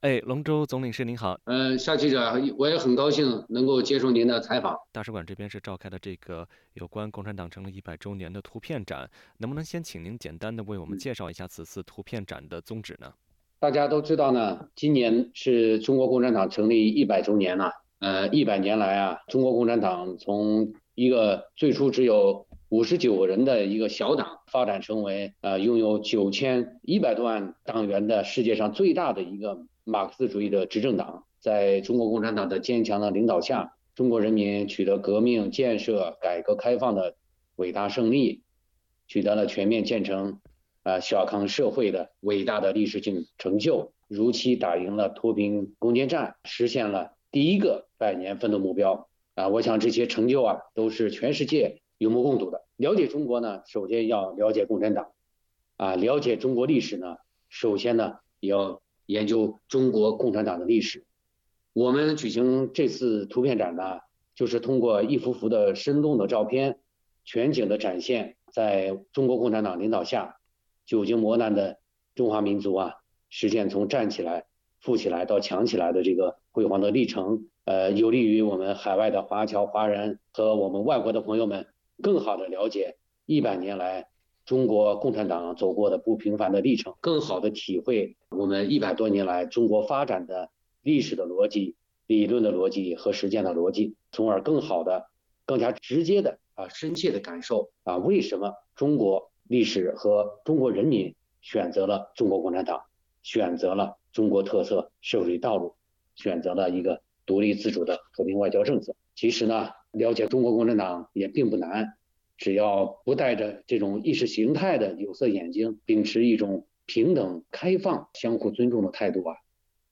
中国驻墨尔本总领事馆举办了名为“中国共产党的100年国际巡展”的线上图片展活动。总领事龙舟在活动开幕式之后接受了本台记者就澳中关系的访谈。